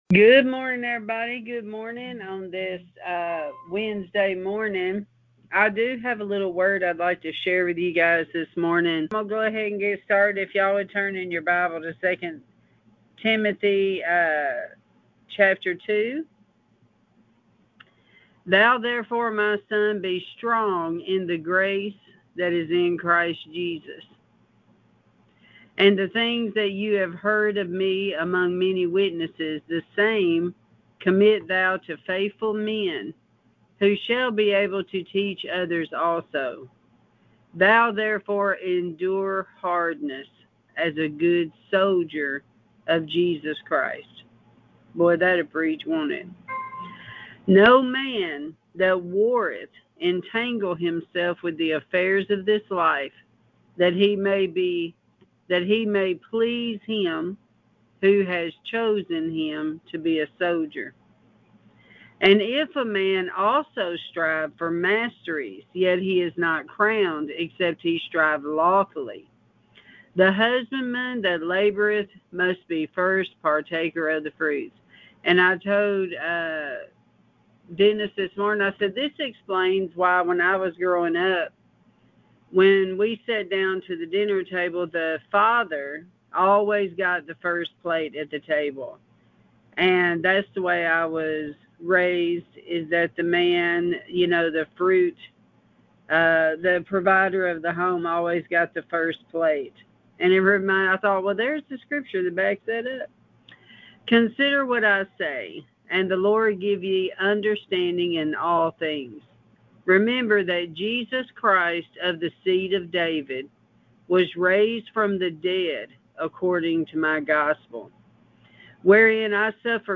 Prayer Calls